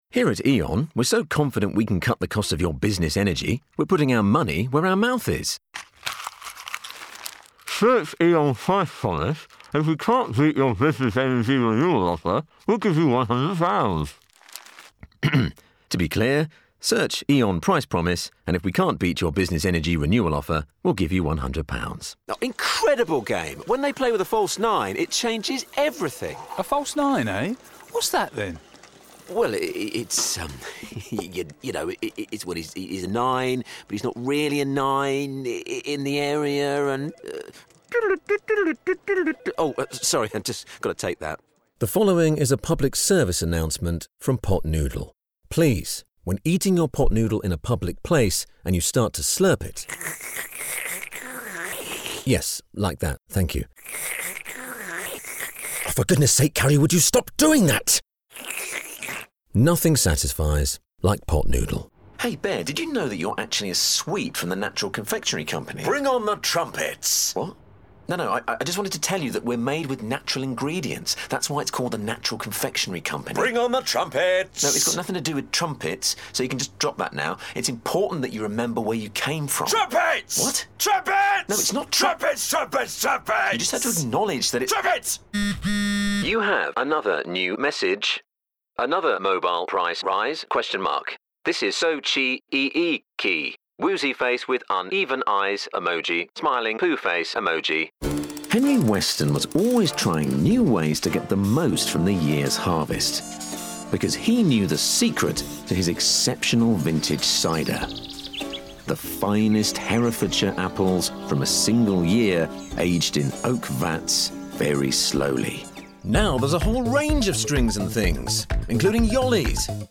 40's London/Neutral, Comedic/Deep/Versatile